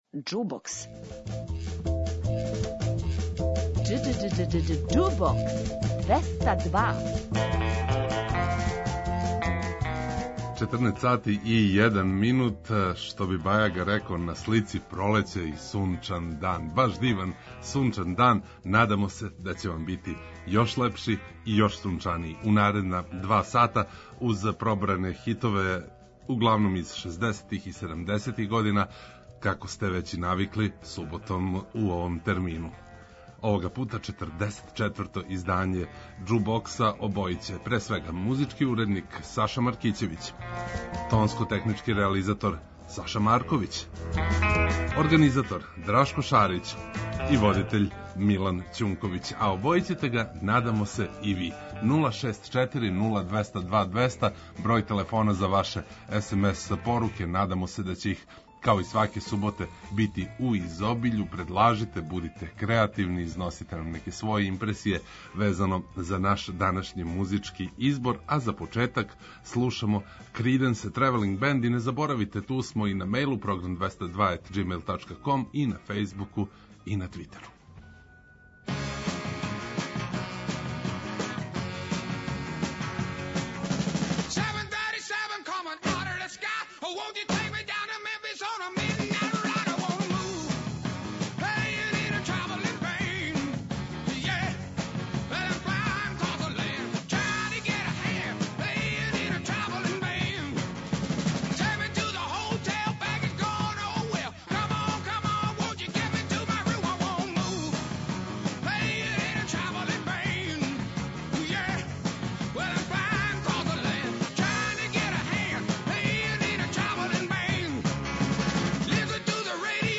преузми : 27.75 MB Џубокс 202 Autor: Београд 202 Уживајте у пажљиво одабраној старој, страној и домаћој музици.